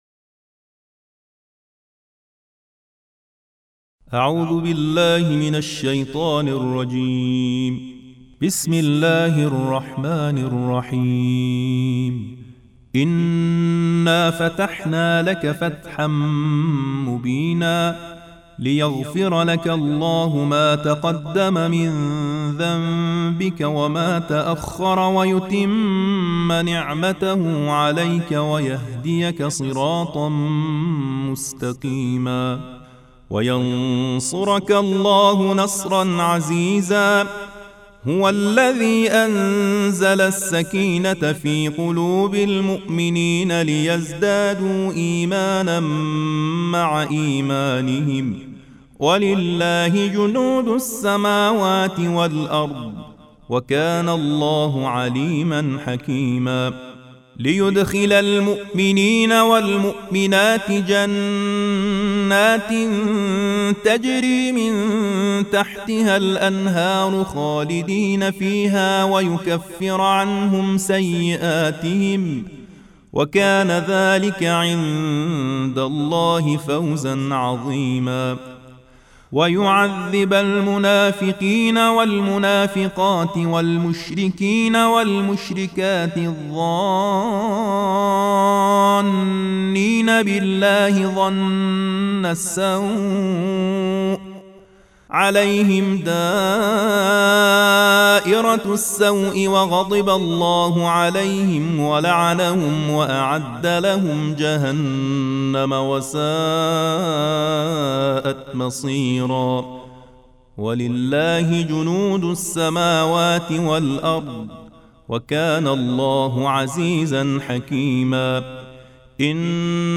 تلاوت سوره فتح
ترتیل سوره مبارکه فتح